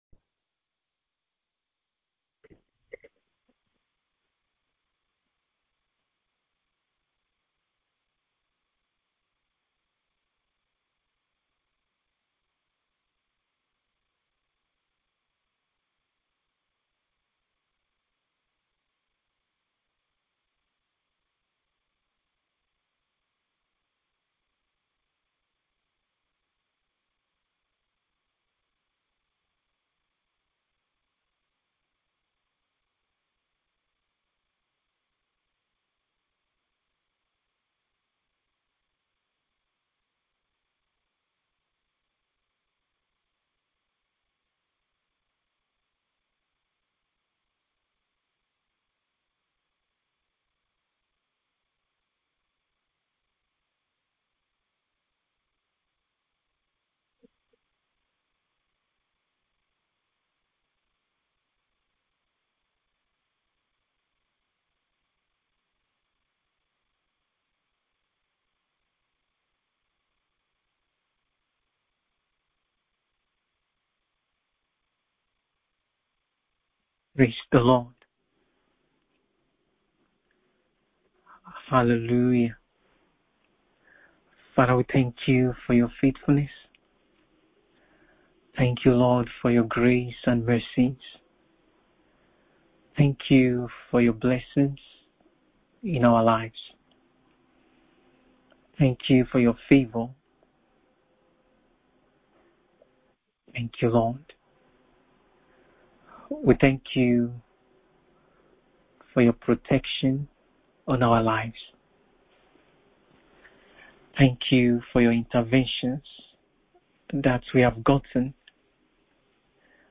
BIBLE STUDY CLASS